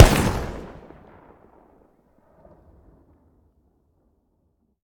weap_mike203_fire_plr_atmos_02.ogg